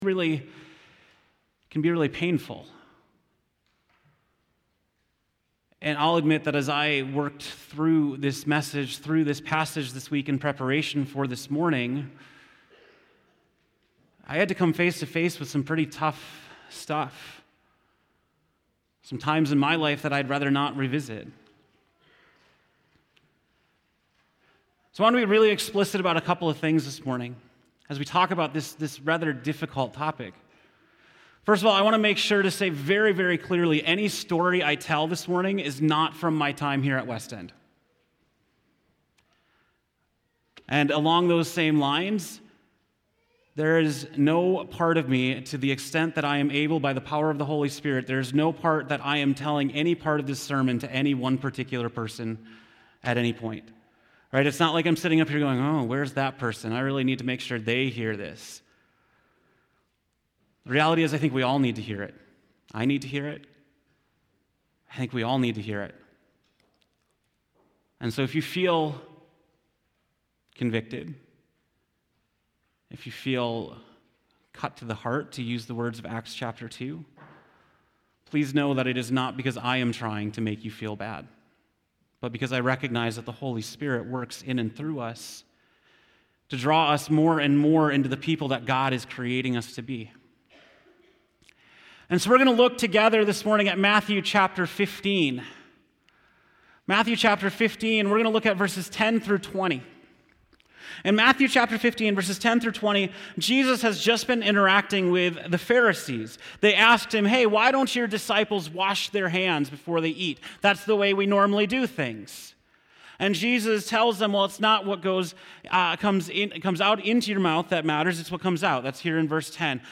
October 13, 2019 (Morning Worship)